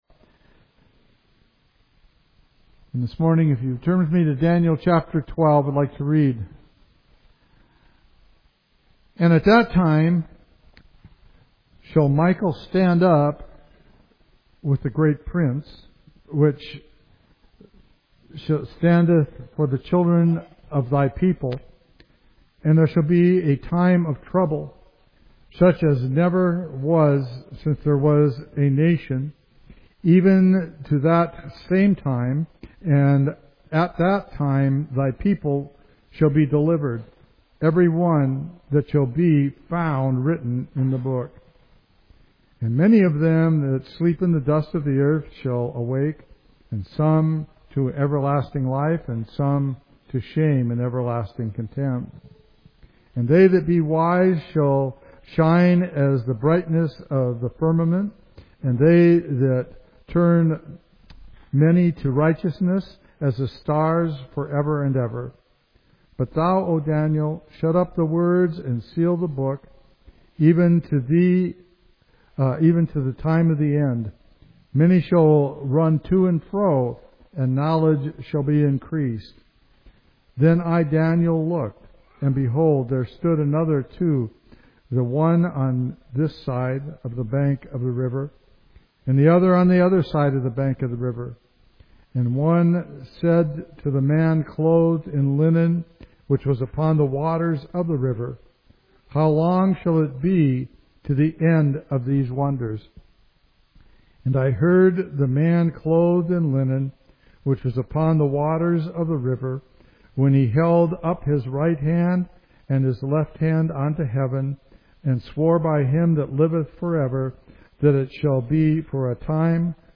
A deep Bible study of Daniel 12 explaining the time of the end, Michael the archangel, the resurrection of the dead, and God’s final deliverance for His people.